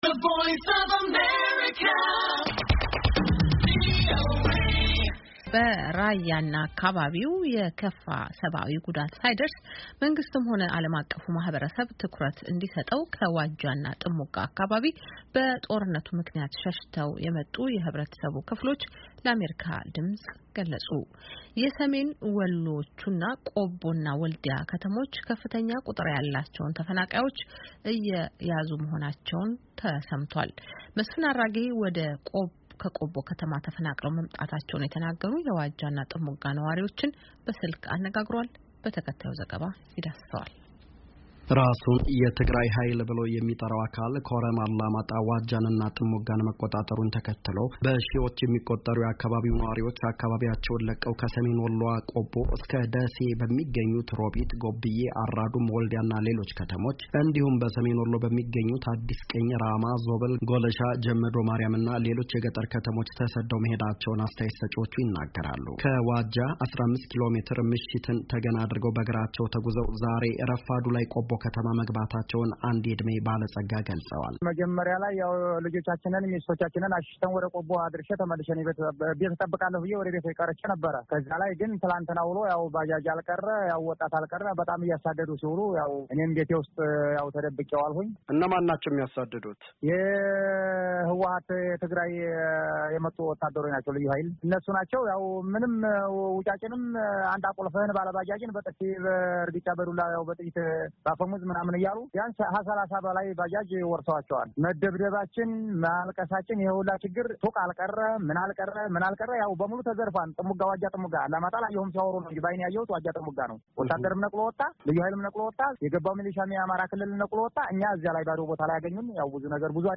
(ተፈናቃዮቹ የሰጡትን አስተያየት ከተያያዘው የድምፅ ፋይል ያዳምጡ)